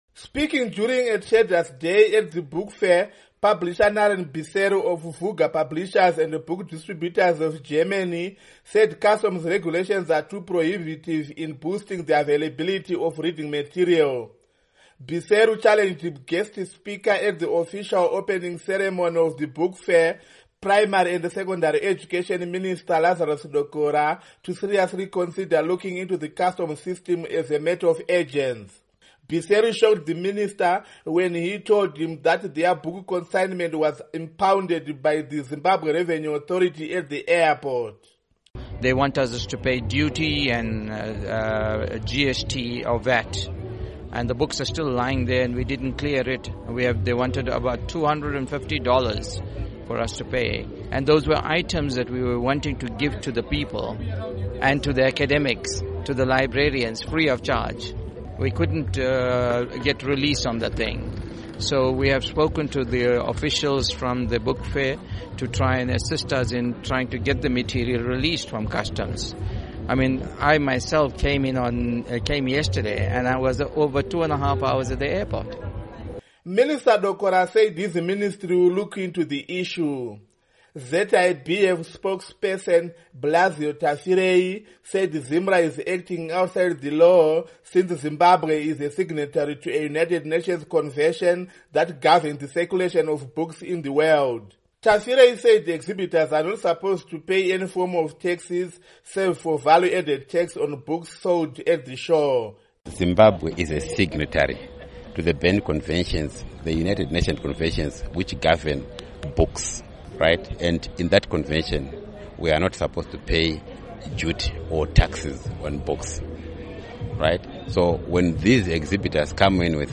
Report On Book Fair